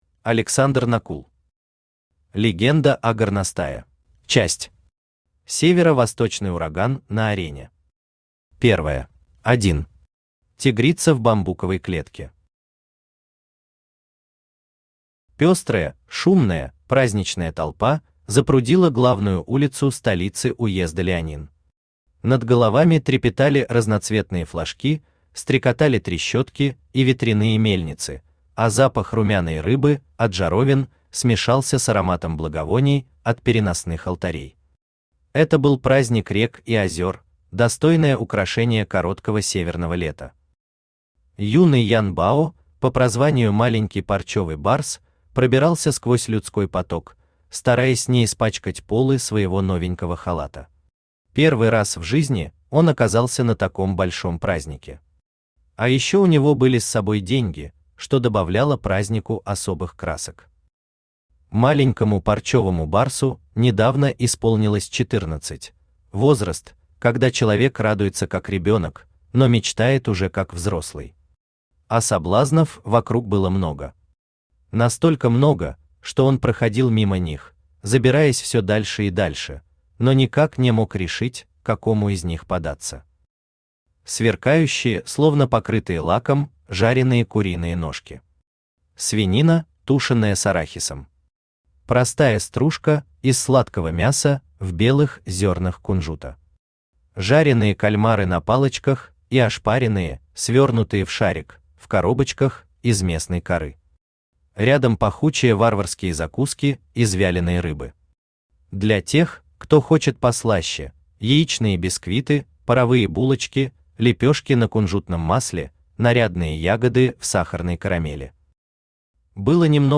Аудиокнига Легенда о Горностае | Библиотека аудиокниг
Aудиокнига Легенда о Горностае Автор Александр Накул Читает аудиокнигу Авточтец ЛитРес.